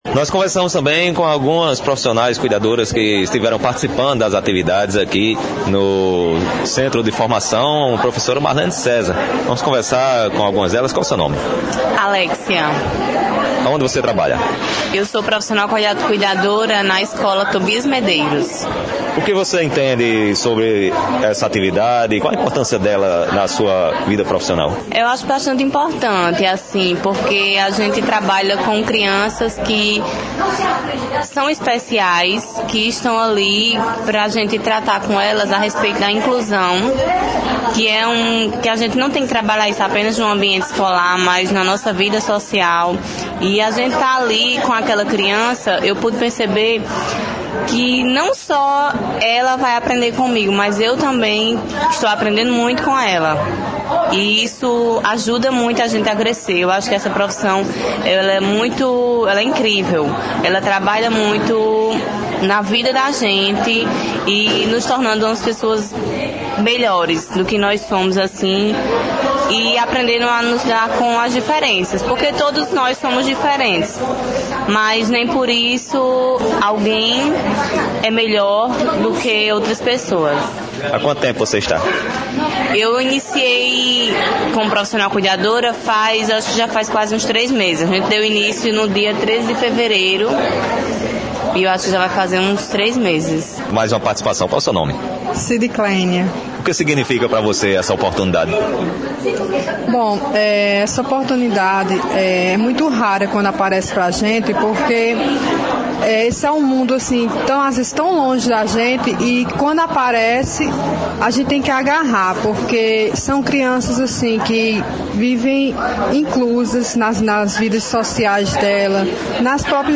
Fala de participantes do Curso de Cuidadores –